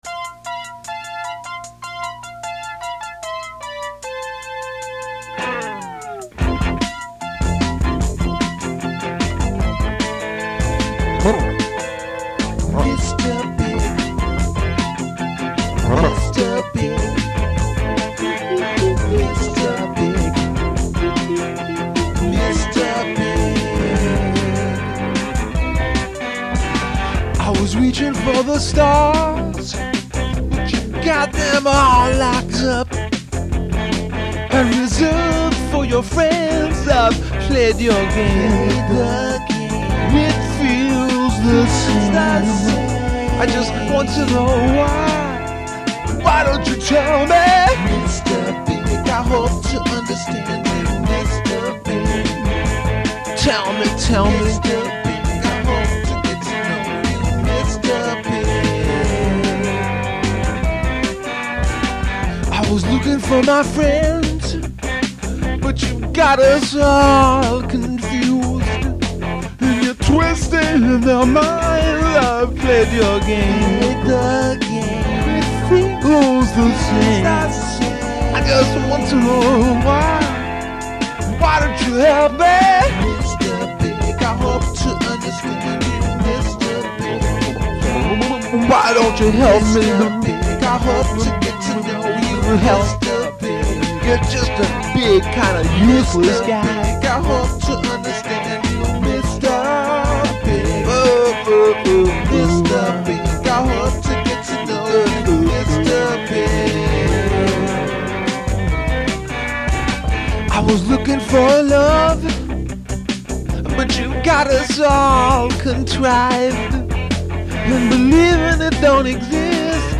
Guitar, Synthesizer and Vocals